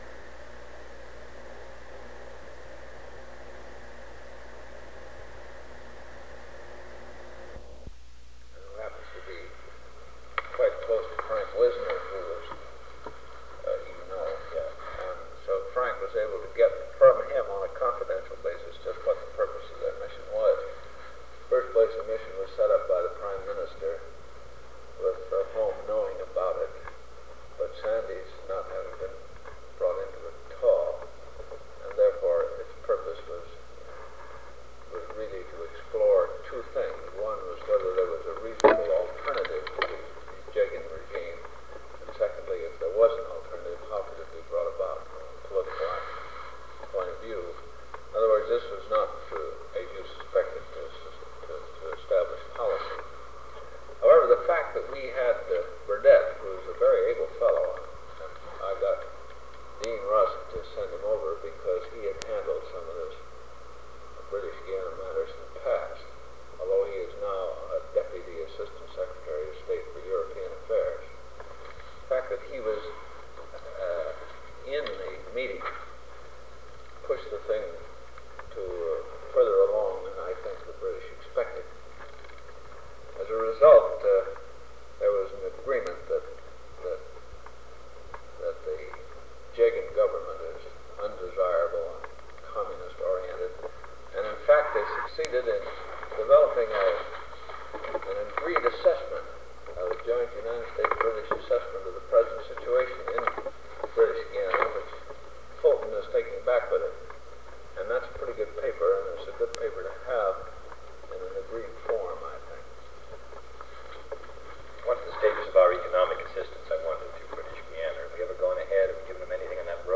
Secret White House Tapes | John F. Kennedy Presidency Meeting with John McCone Rewind 10 seconds Play/Pause Fast-forward 10 seconds 0:00 Download audio Previous Meetings: Tape 121/A57.